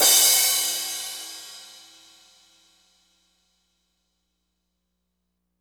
Crashes & Cymbals
Str_Crsh1.wav